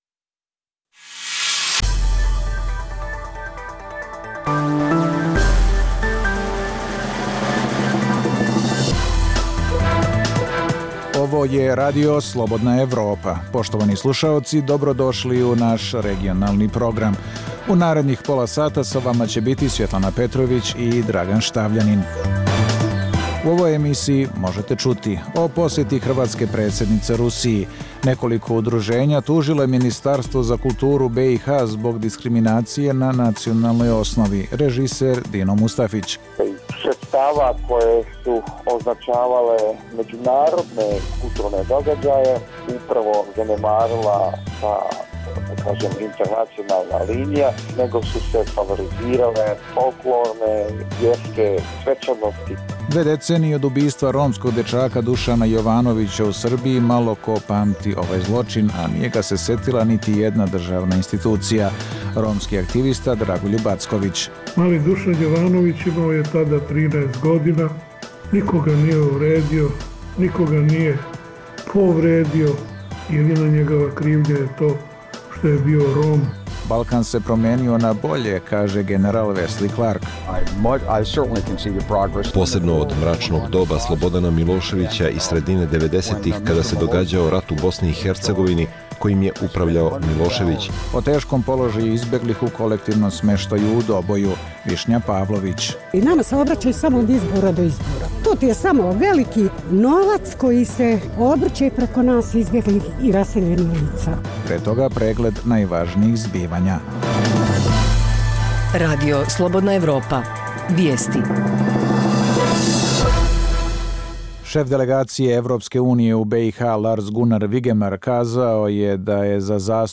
Dnevna informativna emisija Radija Slobodna Evropa o događajima u regionu i u svijetu.